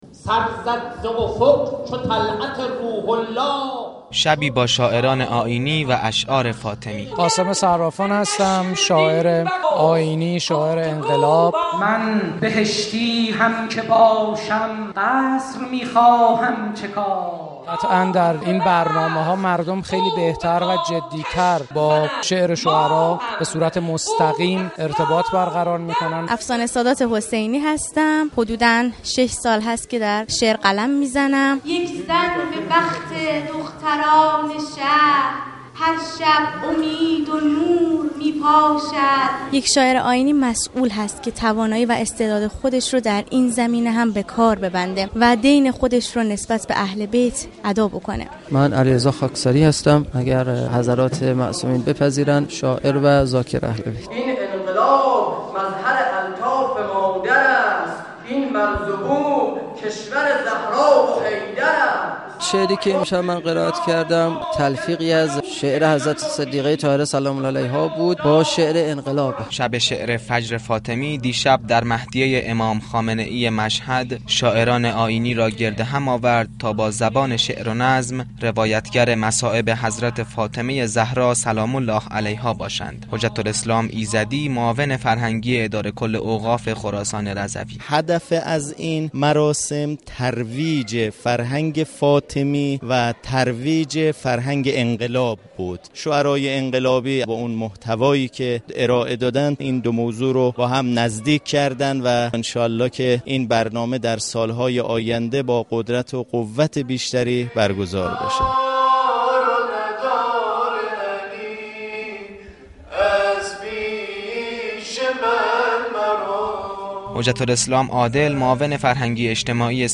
برگزاری شب شعر فاطمی در مشهد مقدس
شب شعر فاطمی در مشهد مقدس میزبان شاعران جوان و پیشكسوت آیینی و انقلابی بود شاعرانی كه سروده های خود را در رثای بانوی دو عالم سرودند.